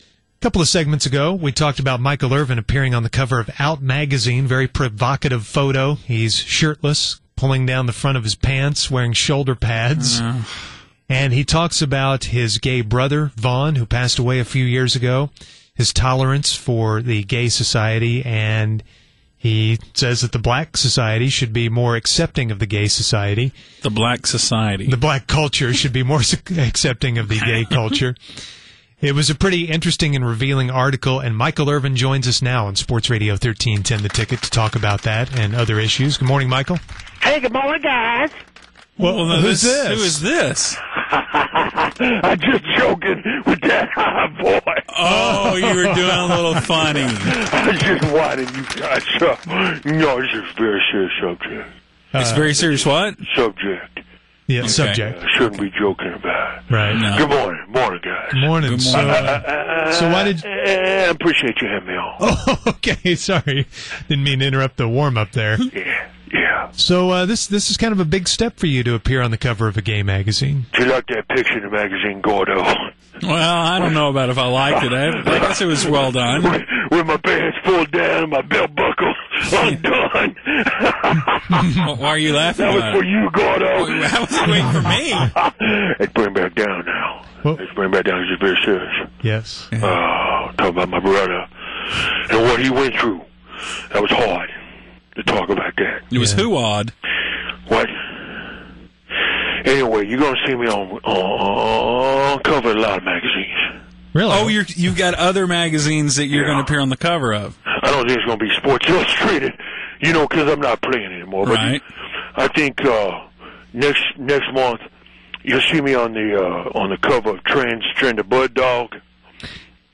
The start of the conversation fake Irvin does his own impersonation of someone else.